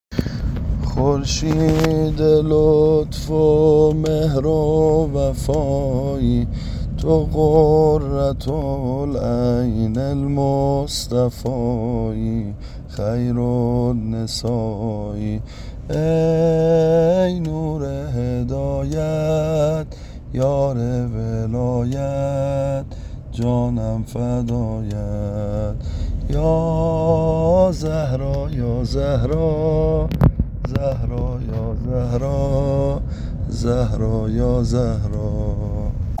عنوان : به این سبک خوانده میشود